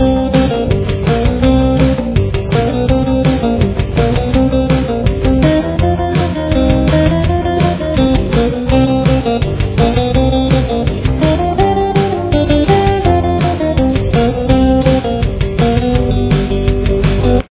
Main Riff